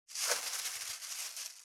616コンビニ袋,ゴミ袋,スーパーの袋,袋,買い出しの音,ゴミ出しの音,袋を運ぶ音,
効果音